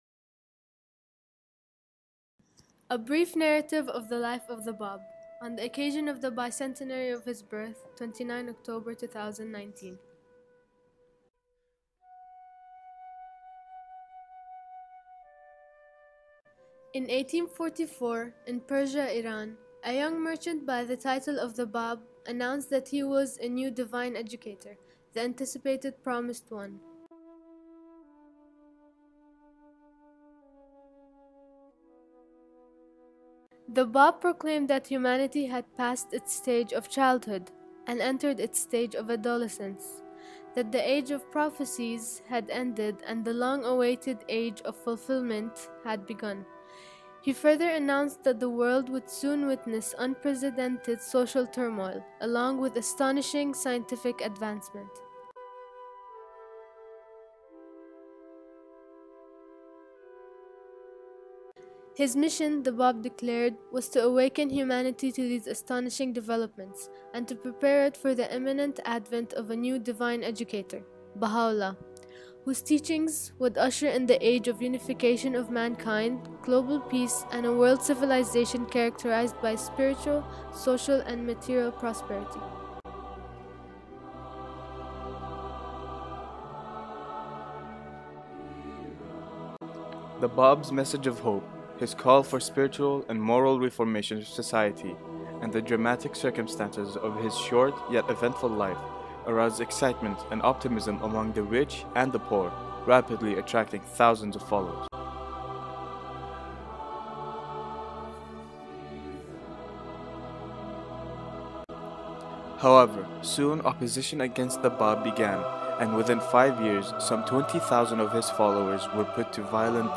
Youth in Qatar present about the life of the Báb